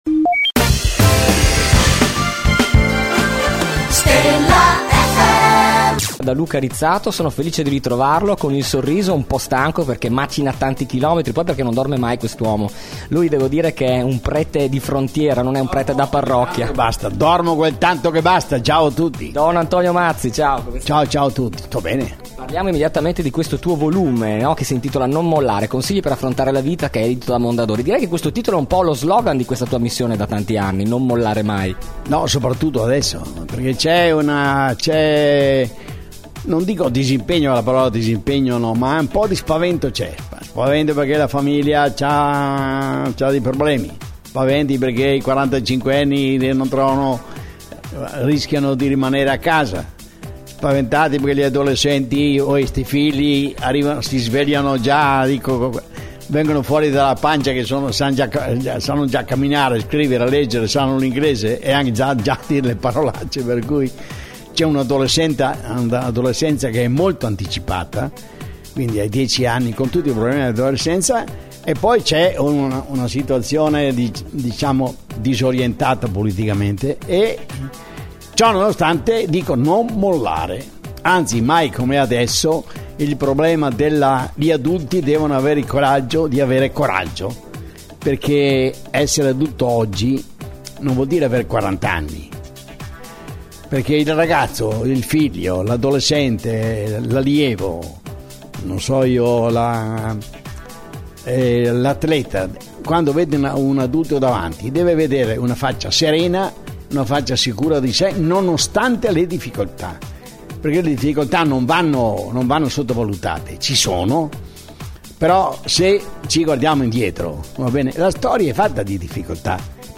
Intervista Don Antonio Mazzi | Stella FM